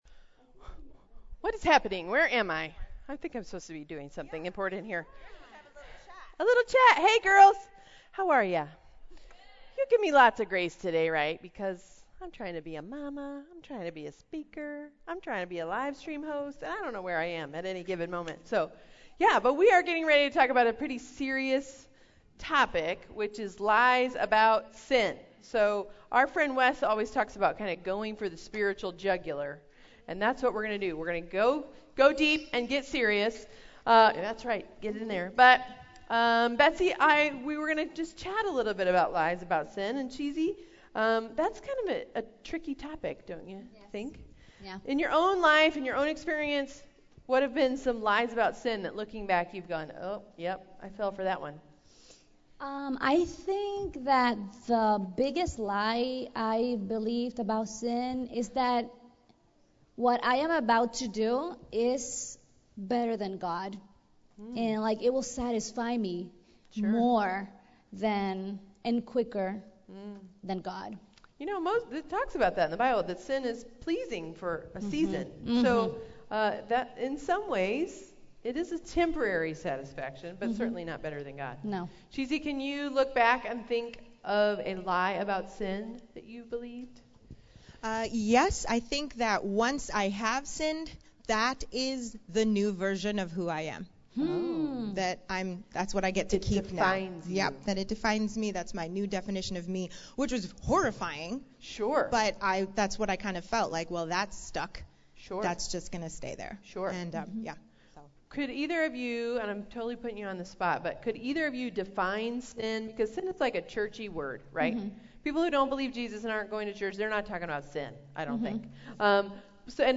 The Truth That Sets Us Free (Teen Session) | True Woman '18 | Events | Revive Our Hearts